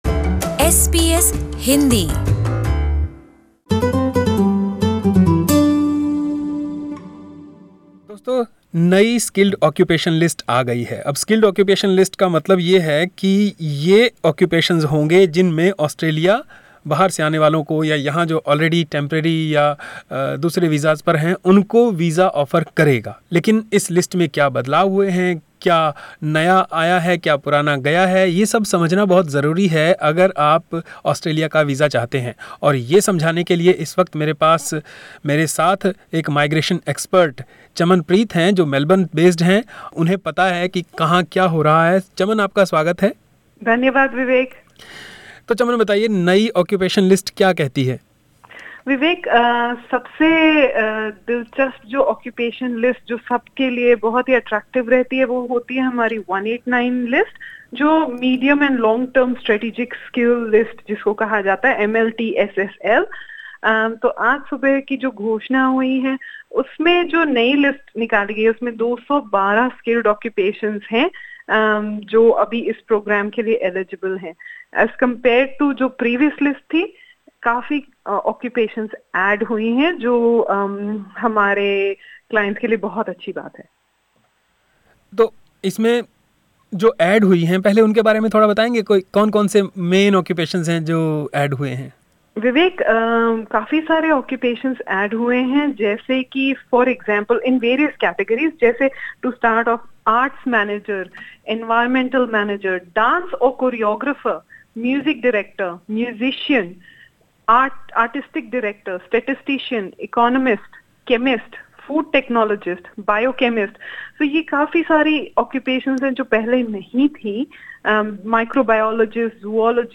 Full Interview: LISTEN TO More occupations added to Australia's new skilled occupations list SBS Hindi 07:50 Hindi Earlier this list had a total of 176 occupations.